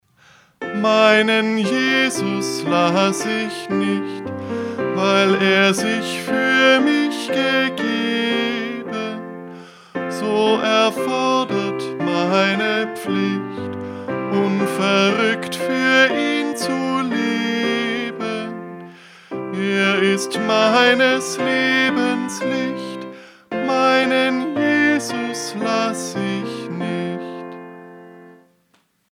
Eingesungen: Liedvortrag (1. Strophe,